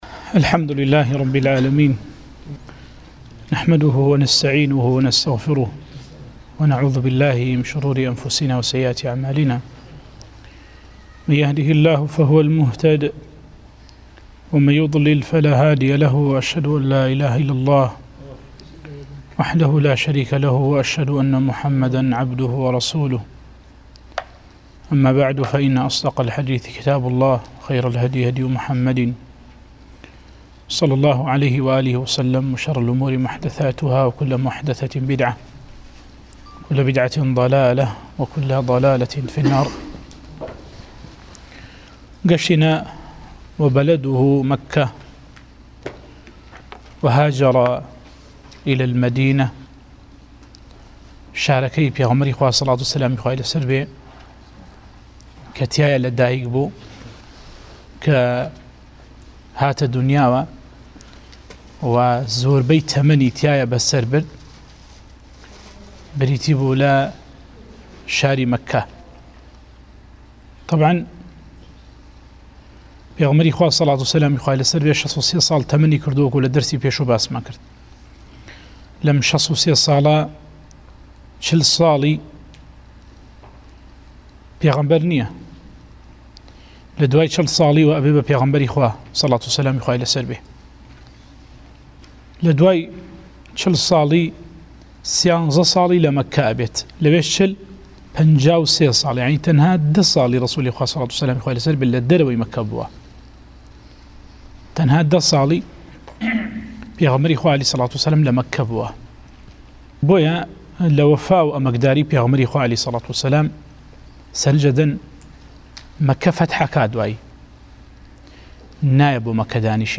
وانەی 26 - ڕاڤه‌ی الأصول الثلاثة للشيخ محمد بن عبد الوهاب